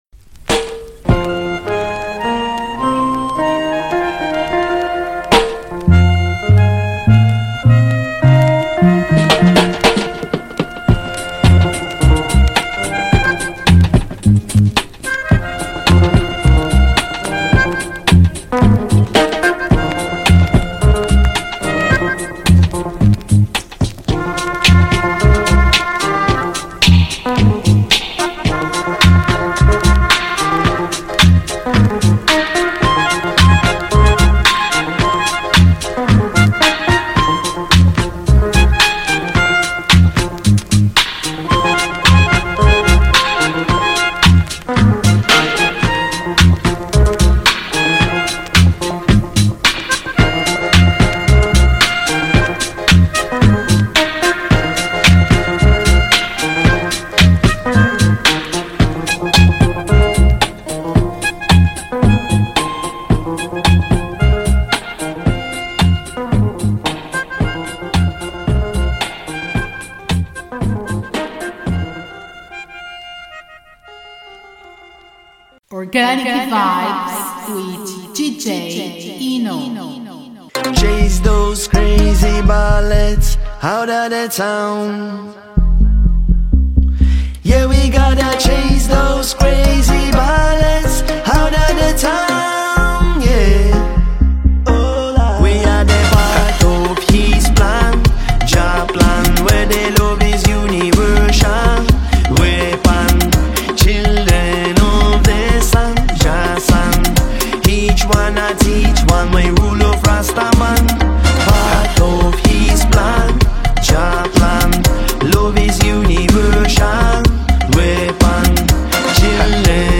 A selection of mainly older tunes but with a few newer ones thrown into the mix with a bit of context added, to help bring out the musical flavours.